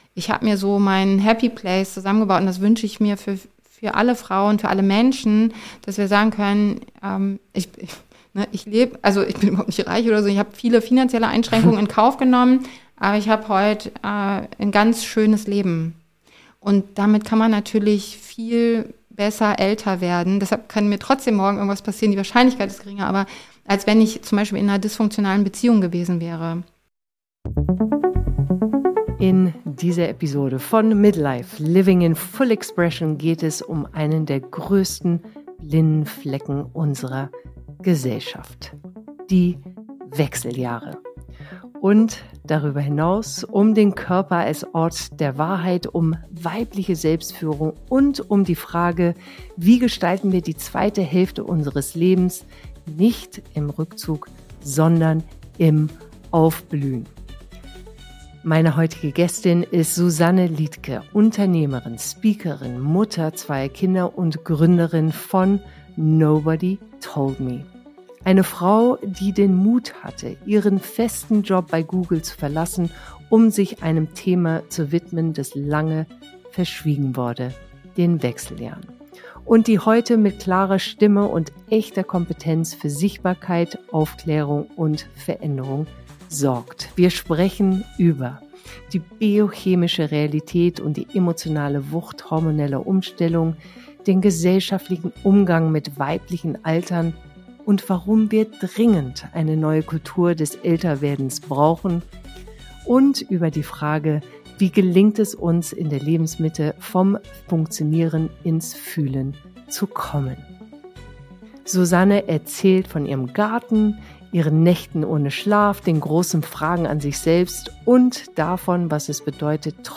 Ein Gespräch über Hormone und Haltung, über Selbstführung statt Selbstoptimierung – und über die Kraft, den eigenen Weg zu gehen, auch wenn er nicht vorgesehen ist.